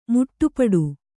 ♪ muṭṭupaḍu